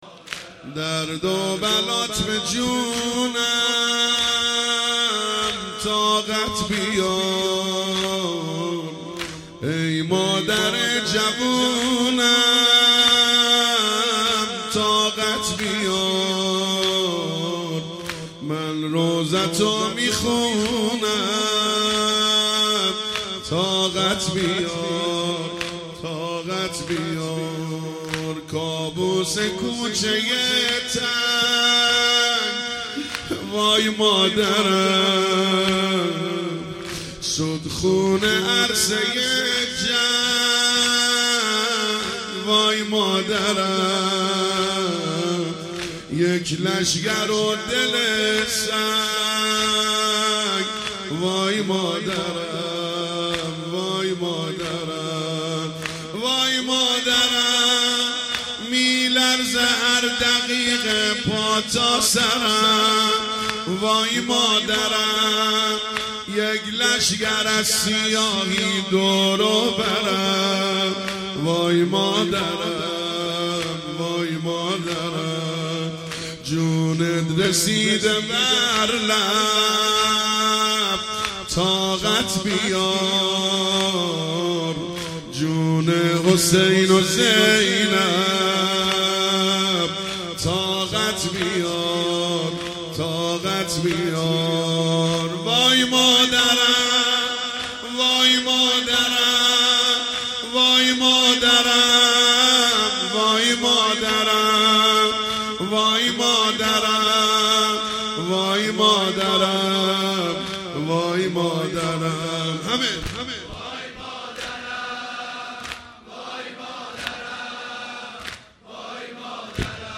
مراسم شب چهارم فاطمیه ۱۳۹۶